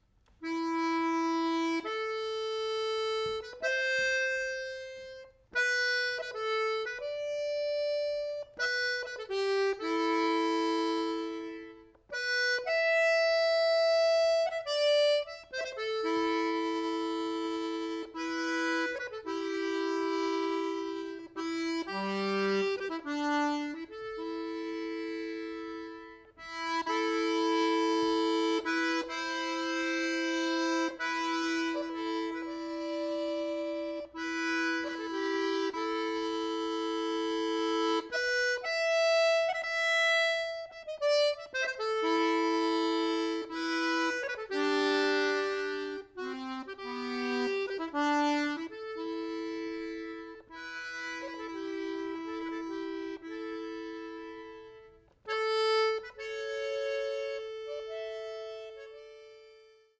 button accordion